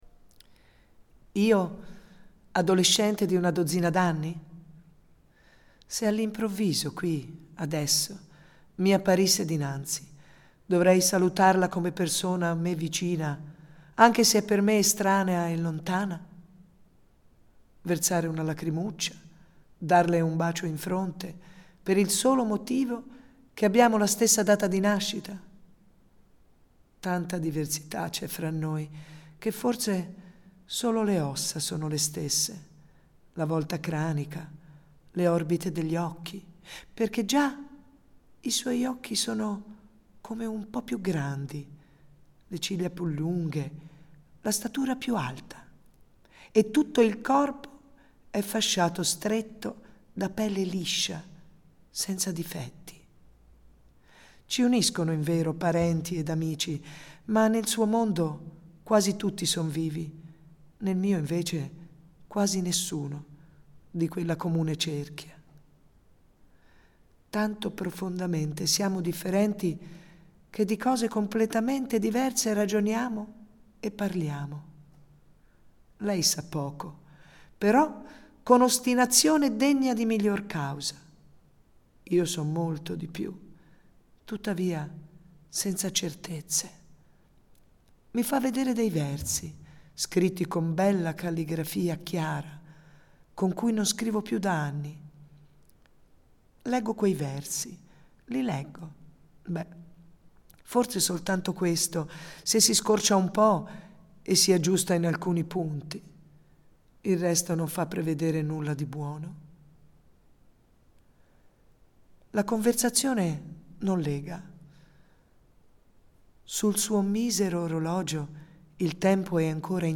dallo spettacolo del 10 Luglio 2015
Nell’ascolto della recitazione degli attori, diventa esplicita anche un’altra caratteristica comune a molte delle sue poesie, cioè la loro teatralità intrinseca, che forse deriva proprio dall’immediatezza del loro stile: questo permette di interpretarle dando voce e vita direttamente agli stessi protagonisti delle poesie.